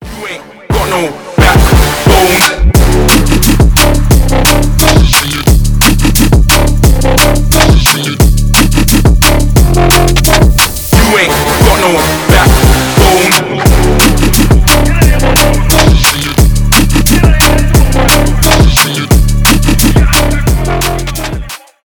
драм энд бейс
басы